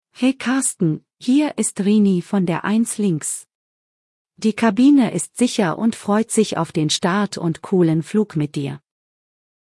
CallCabinSecureTakeoff.ogg